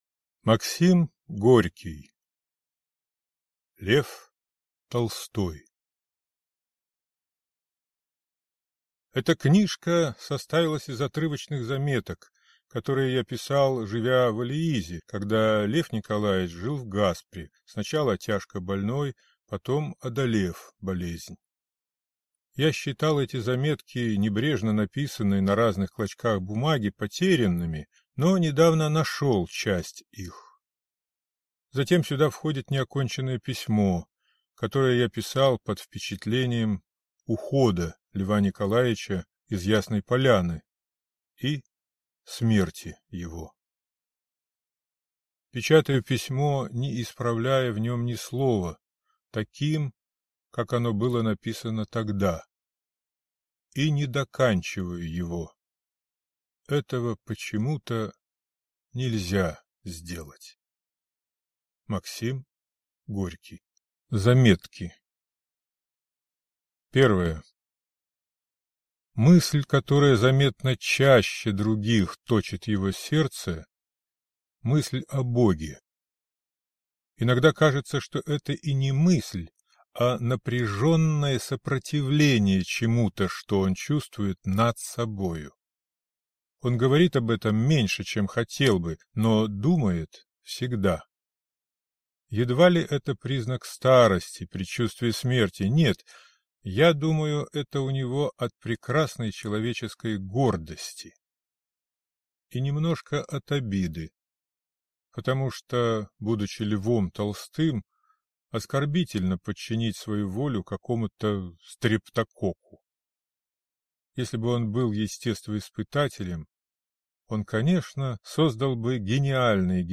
Аудиокнига Лев Толстой | Библиотека аудиокниг